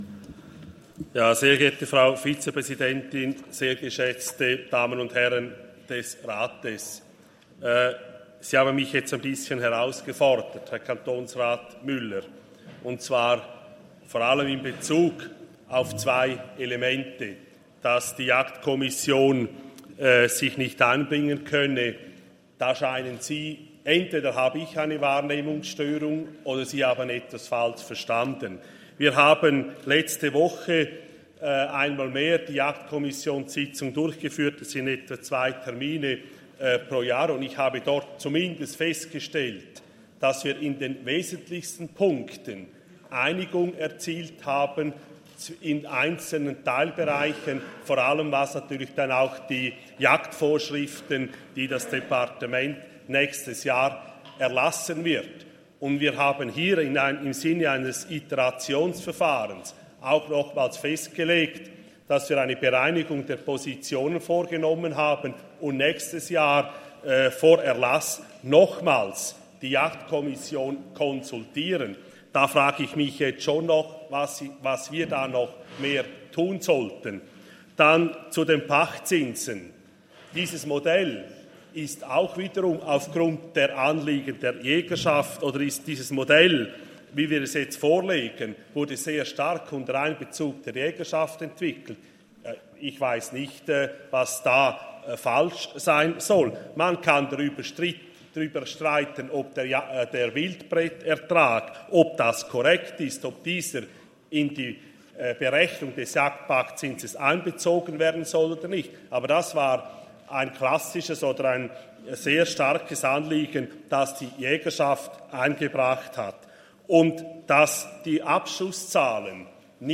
27.11.2023Wortmeldung
Session des Kantonsrates vom 27. bis 29. November 2023, Wintersession